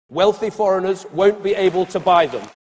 wont_be_able_cameron.mp3